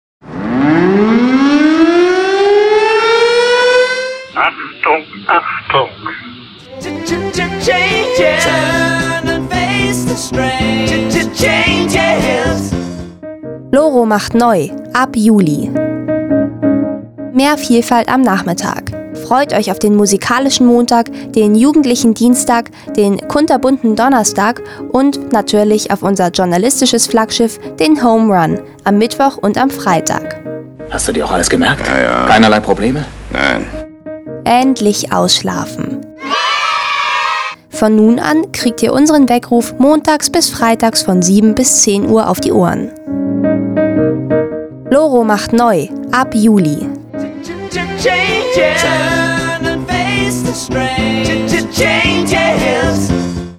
Trailer zum Nachhören
Für den Ohrwurm aus unserem Trailer zum Nachhören: David Bowie† – Changes